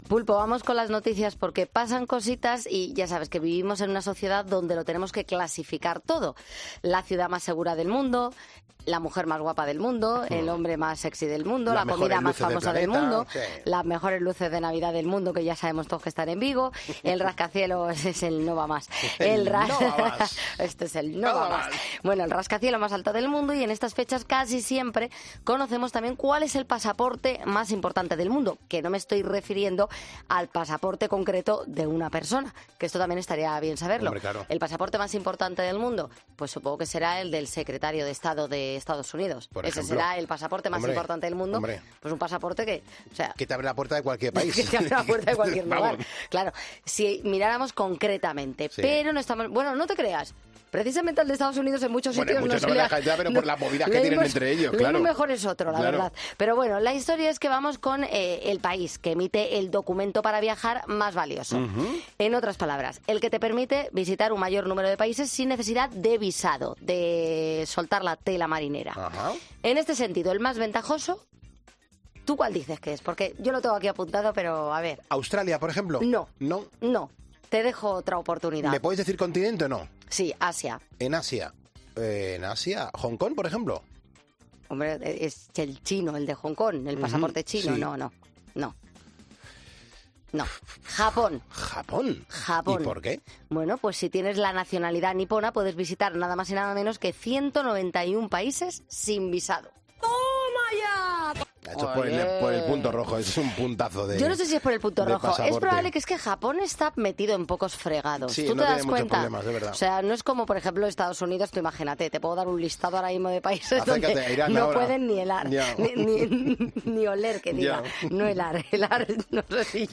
Escuchamos al Rey porque, el miércoles, Elvis Presley habría cumplido 85 años...